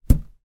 balloon_hits.ogg